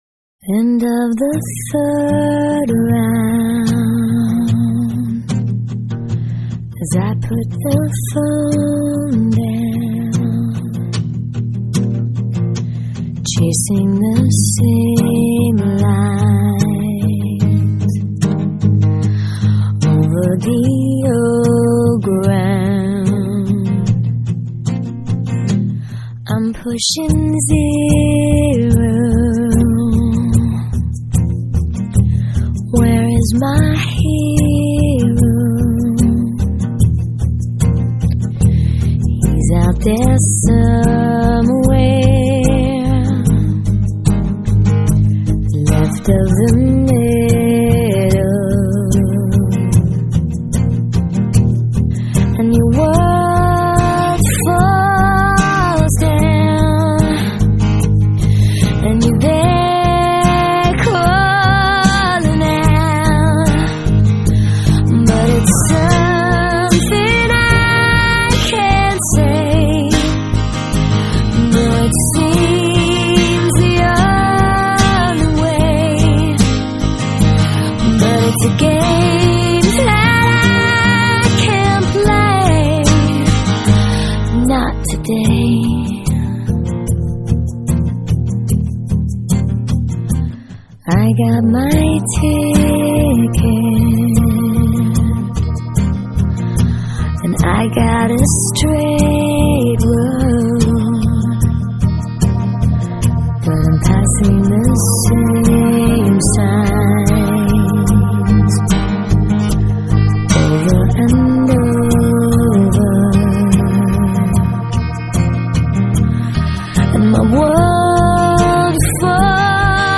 Pop Rock, Alternative Pop